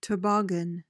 PRONUNCIATION:
(tuh-BOG-uhn)